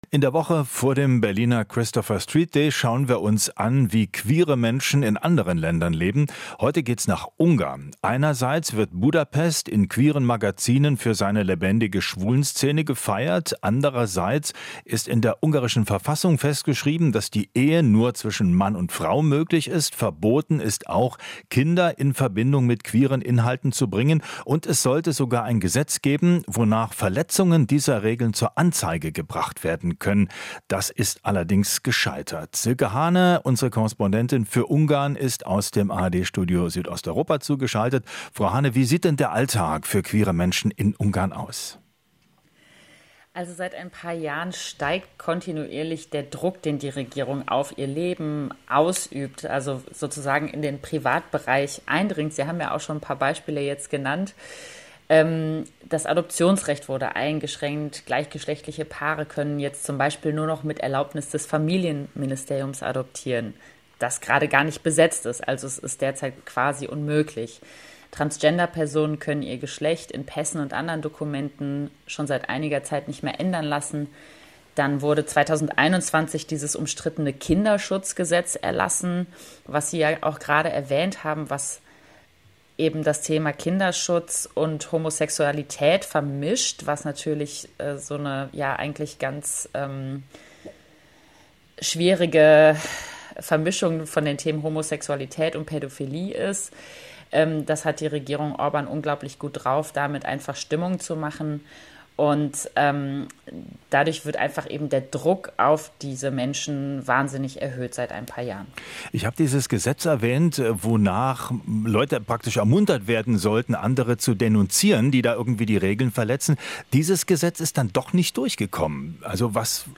Interview - Ungarn erhöht Druck auf Menschen aus der LGBTQ-Community